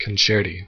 Ääntäminen
Ääntäminen US Tuntematon aksentti: IPA : /kʌnˈtʃɛɹˌti/ Haettu sana löytyi näillä lähdekielillä: englanti Käännöksiä ei löytynyt valitulle kohdekielelle. Concerti on sanan concerto monikko.